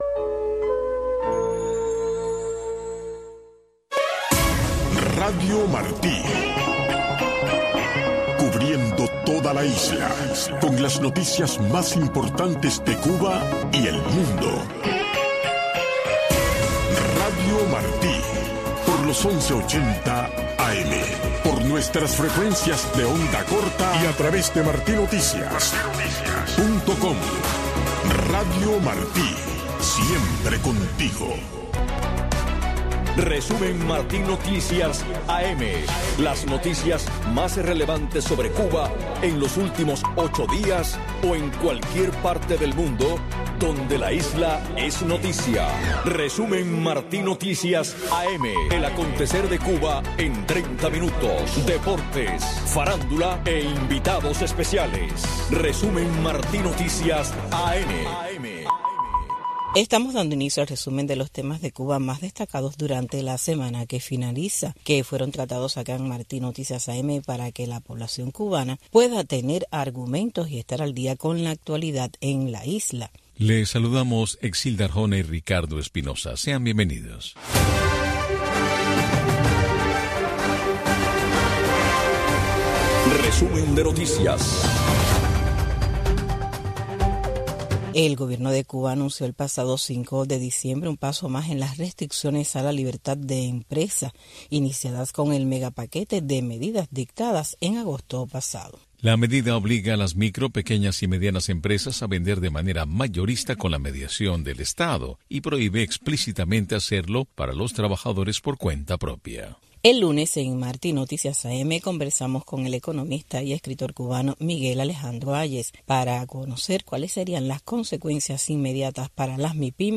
Un resumen ágil y variado con las noticias más relevantes que han ocurrido en Cuba en los últimos 8 días o en cualquier parte del mundo donde un tema sobre la isla es noticia, tratados con invitados especiales. Media hora de información, deportes y farándula.